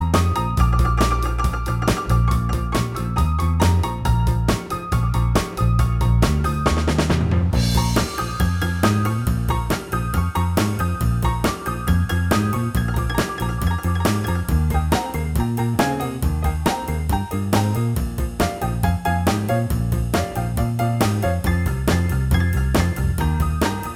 Minus Lead Guitar Rock 'n' Roll 2:37 Buy £1.50